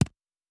pgs/Assets/Audio/Fantasy Interface Sounds/UI Tight 04.wav
UI Tight 04.wav